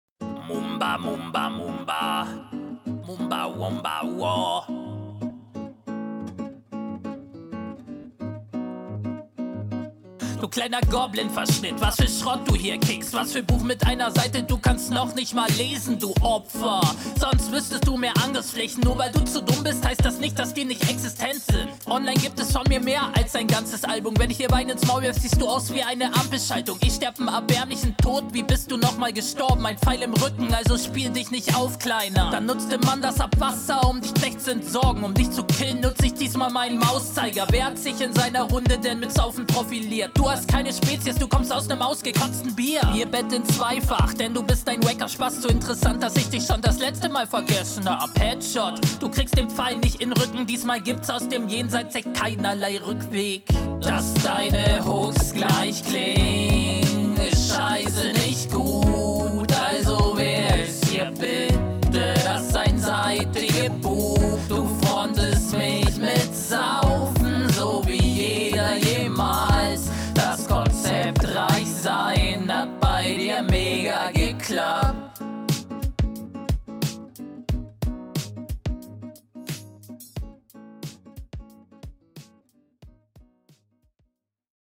Warum ist der Beat so weit rechts?